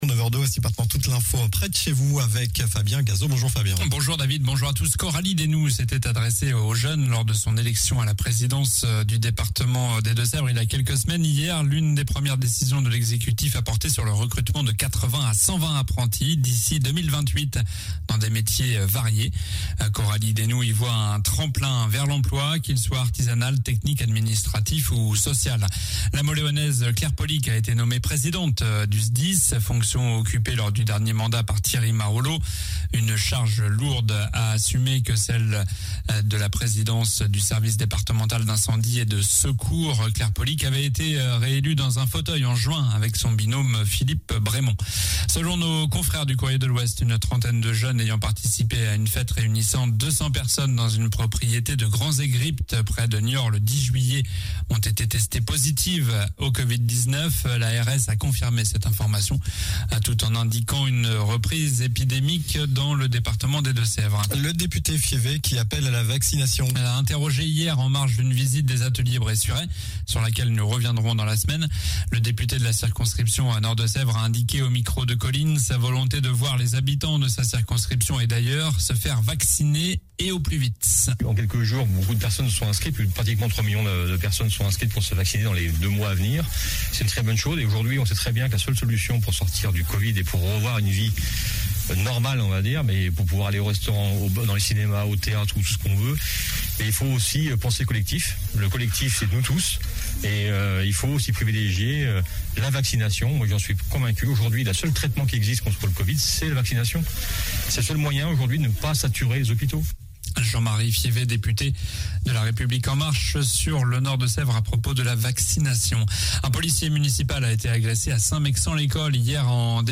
Journal du mardi 20 juillet (matin)